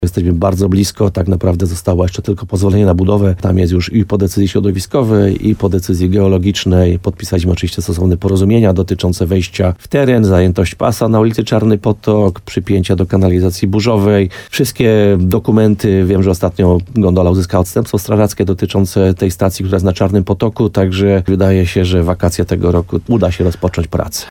Jak powiedział w programie Słowo za Słowo w radiu RDN Nowy Sącz burmistrz Krynicy-Zdroju Piotr Ryba, Polskie Koleje Linowej, czyli inwestor kolejki gondolowej, wykonał już w tej kwestii ogrom pracy.